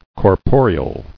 [cor·po·re·al]